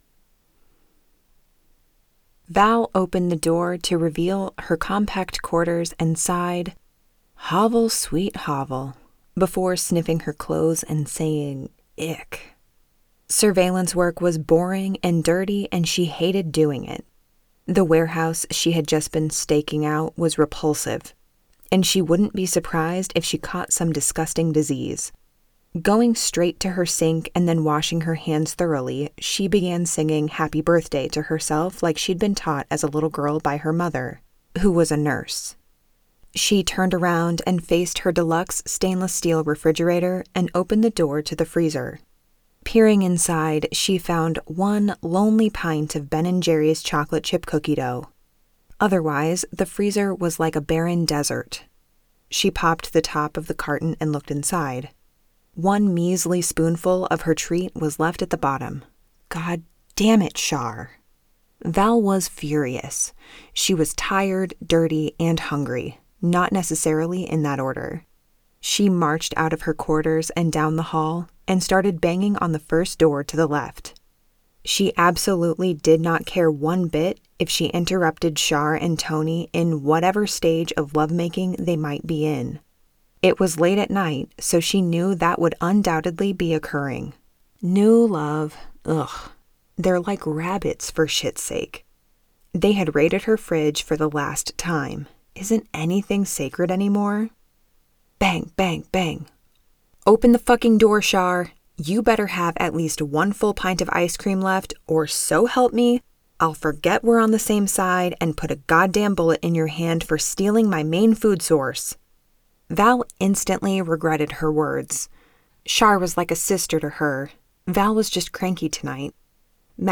The Organization by Annette Mori & Erin O’Reilly [Audiobook]
Narrator: